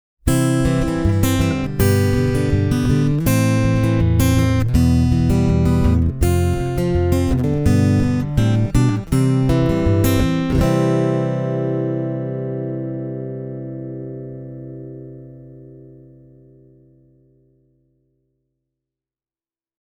This is what this LTD is meant for, and the guitar delivers a quality piezo sound with plenty of dynamics: